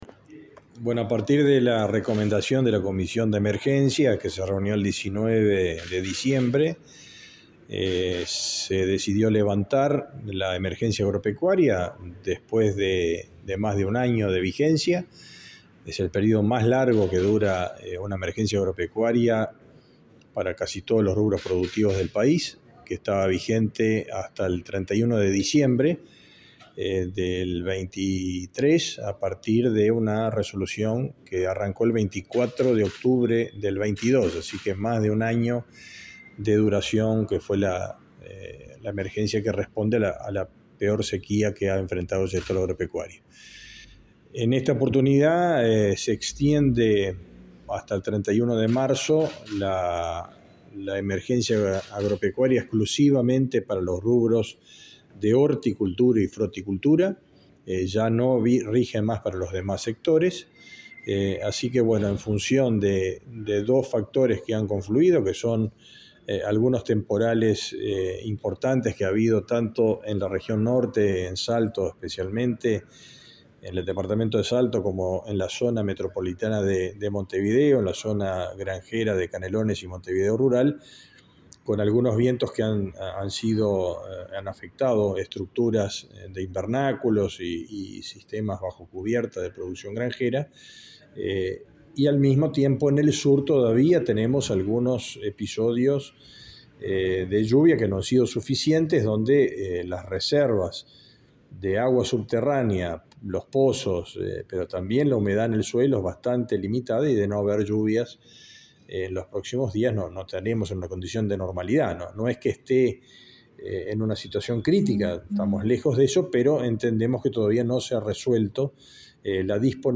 Entrevista al ministro de Ganadería, Fernando Mattos
El ministro de Ganadería, Fernando Mattos, dialogó con Comunicación Presidencial, acerca de la decisión del Gobierno de levantar la emergencia